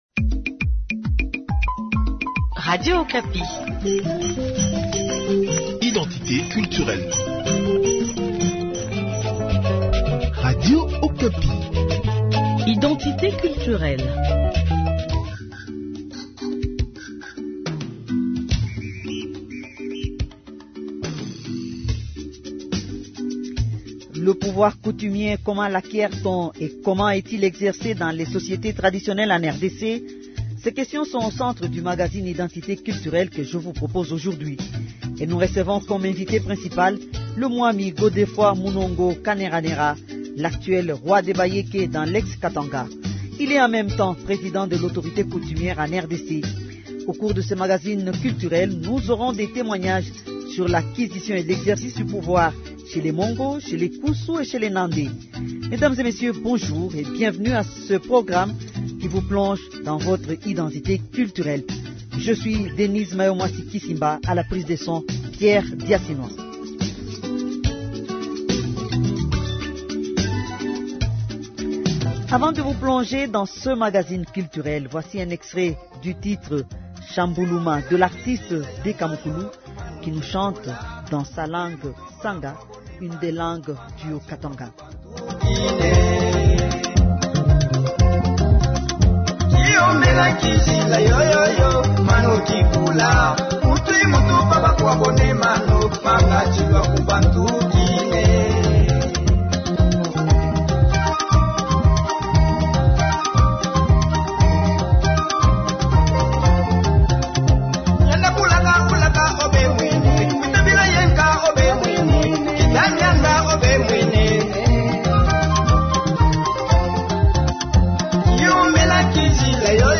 Et nous recevons comme invité principal, le Mwami Godefroid Munongo Kaneranera, l’actuel roi des Bayeke dans l’ex Katanga.
Au cours de ce magazine culturelle, nous aurons des témoignages sur l’acquisition et l’exercice du pouvoir chez les Mongo, les Kusu et les Nande.